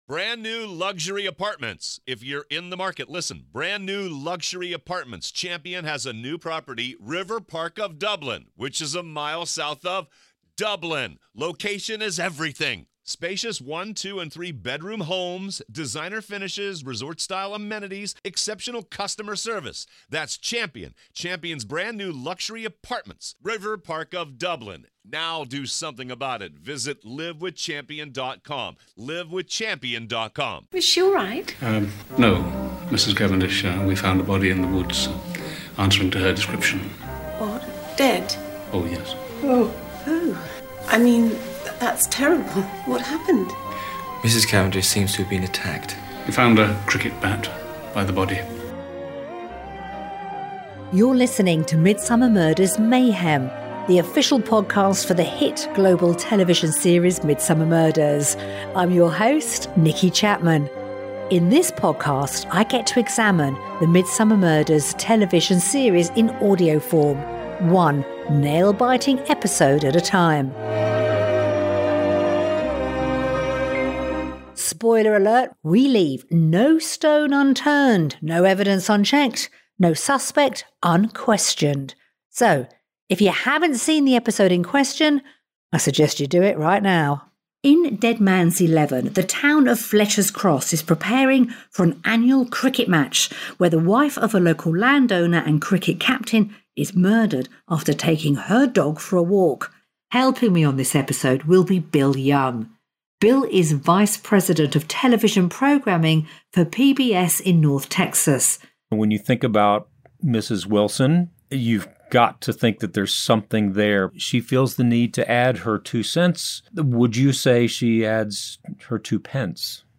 Plus, we'll finish things off with a round of our superfan face off quiz, hosted by award-winning Scottish comedian, actor, and most importantly, die-hard Midsomer Murders enthusiast, Ashley Storrie.